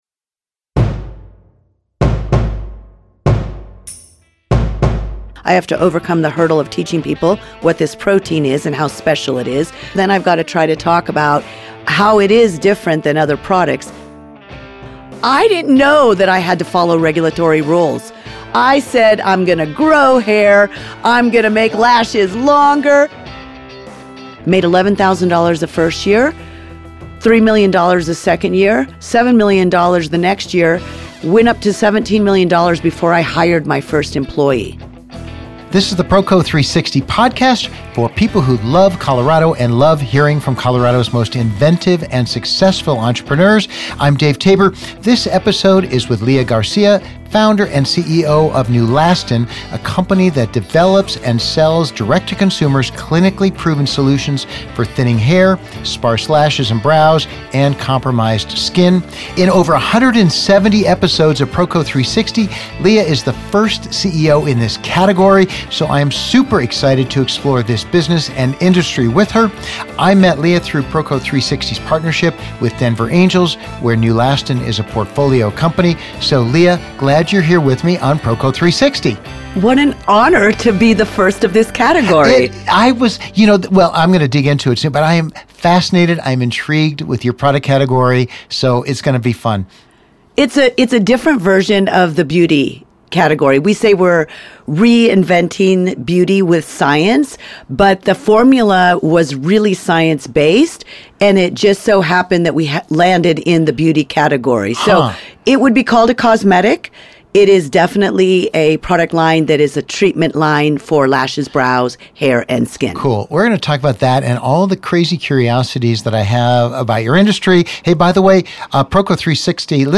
I had pent up demand for this interview with questions like: Does efficacy or marketing drive sales?